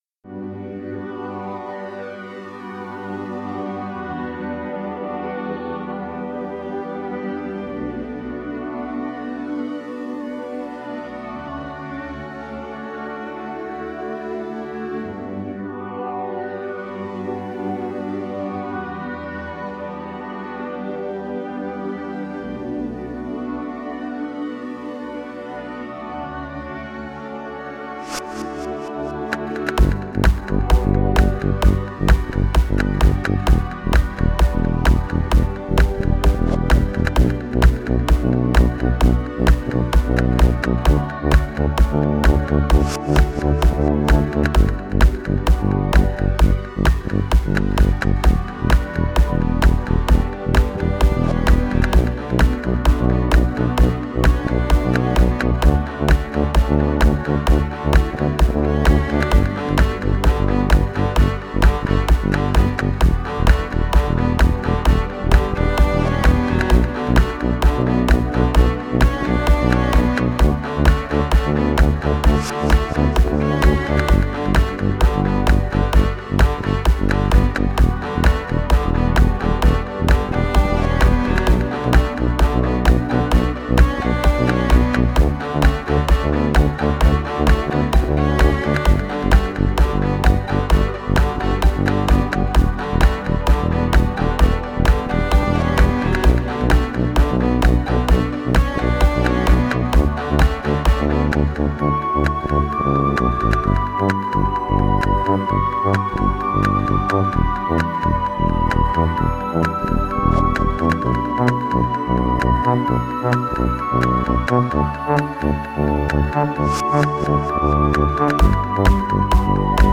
Category: Ambient
but somewhat simpler and lighter in its execution.